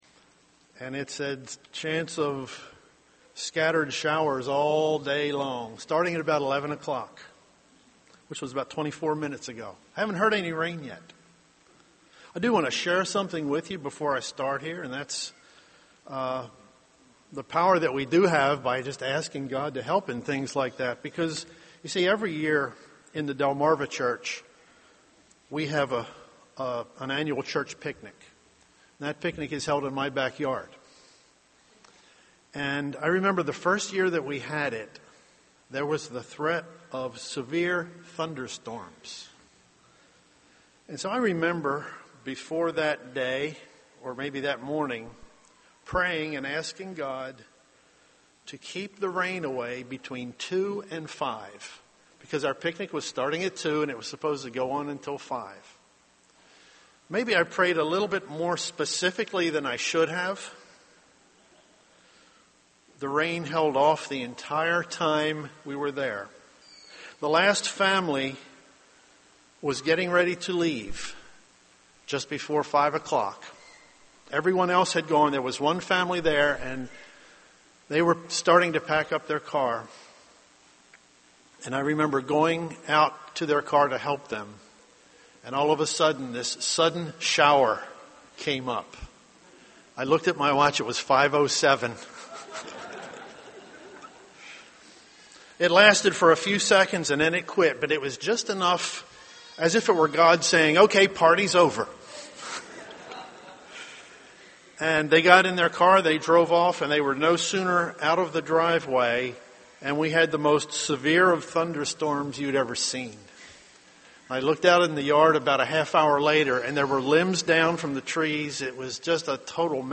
This sermon was given at the Jekyll Island, Georgia 2012 Feast site.